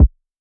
Mr Lonely Kick.wav